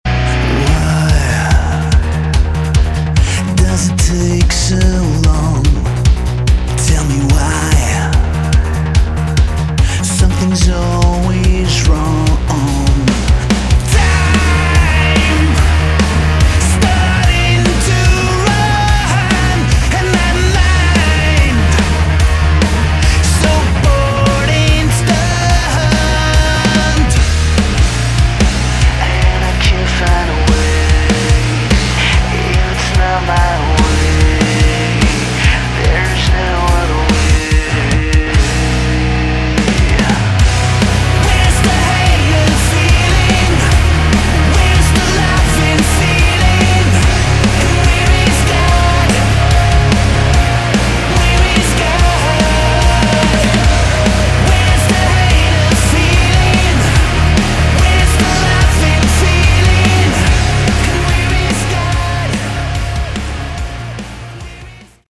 Category: Modern Hard Rock
vocals
bass
keyboards, guitars
drums